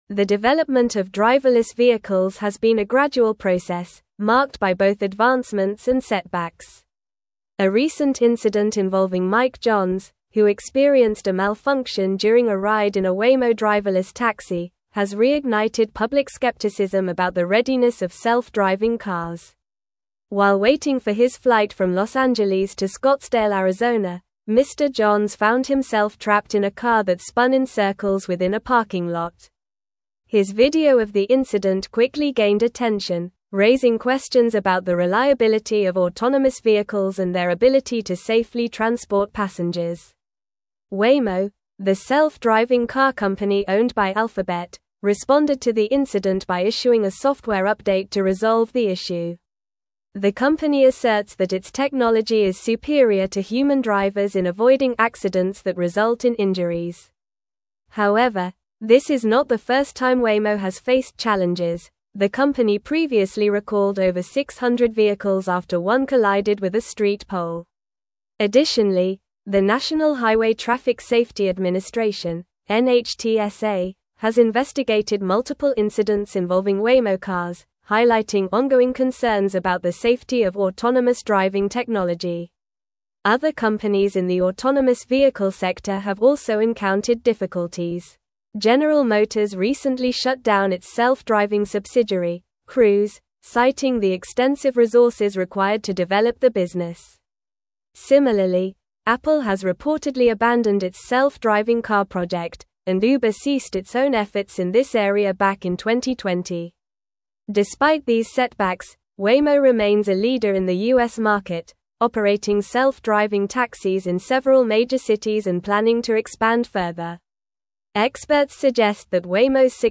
Normal
English-Newsroom-Advanced-NORMAL-Reading-Challenges-Persist-in-the-Adoption-of-Driverless-Vehicles.mp3